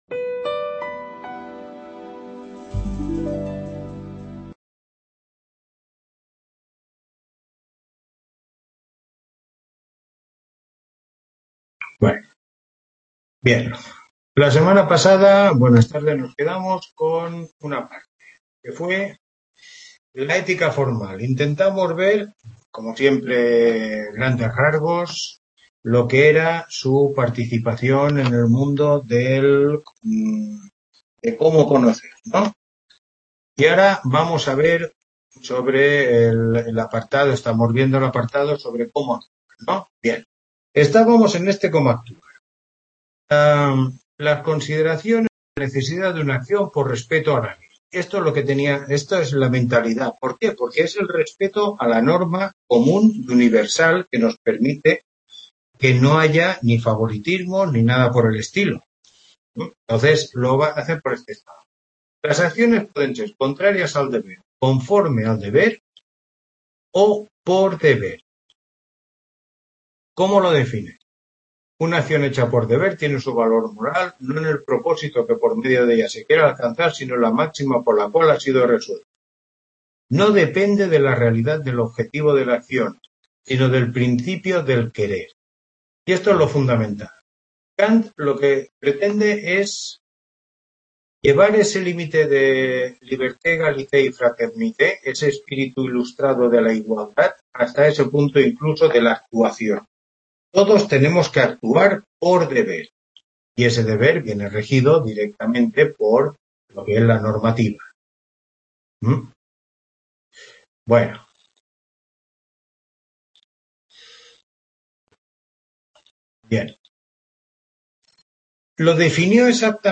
Tutoría 5